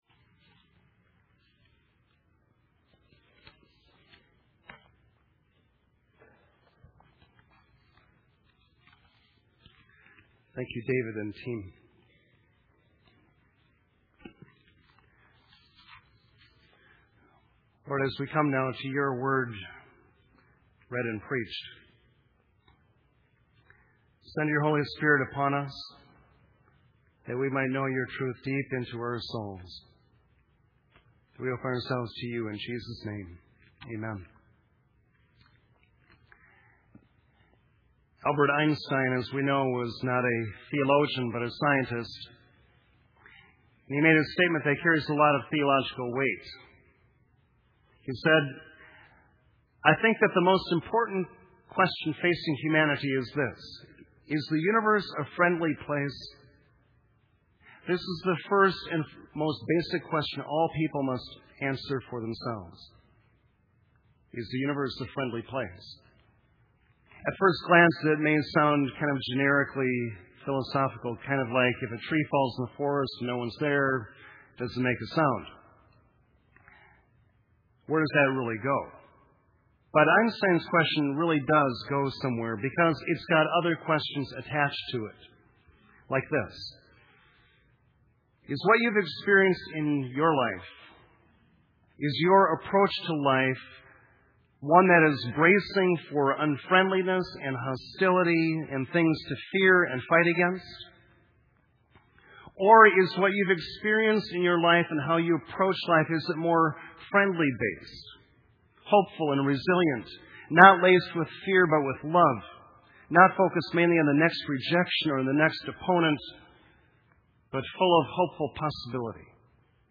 Love Casts Out Fear – July 28th Worship Service
Sermon Audio